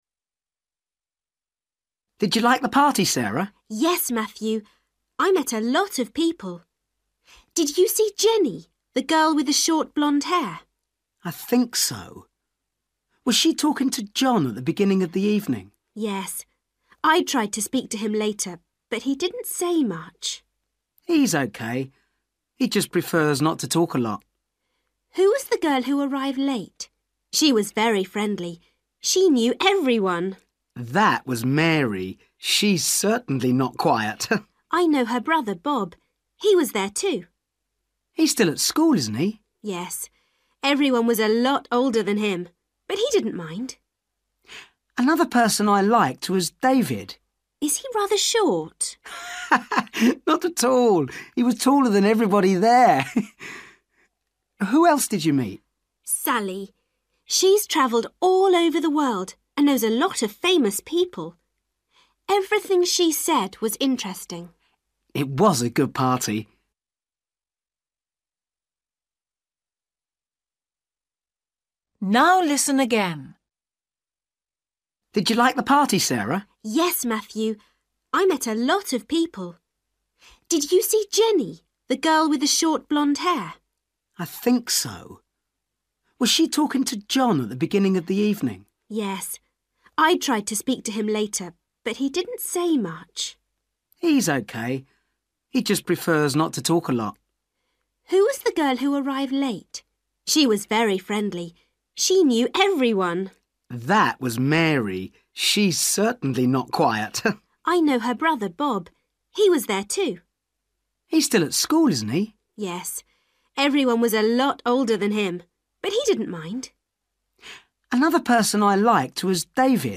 Listen to Sarah and Matthew talking about the people they met at a party.